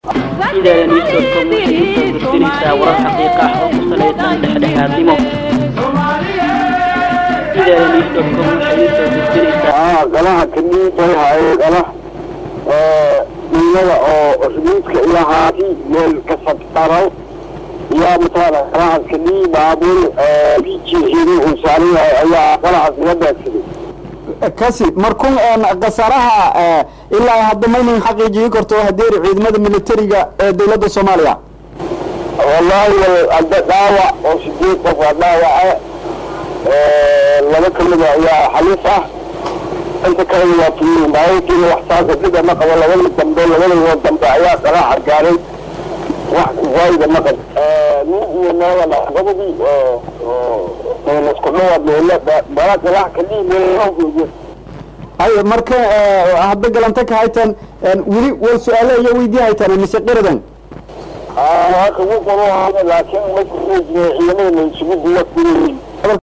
Halkan Ka Dhageyso Waresyisaga Wariye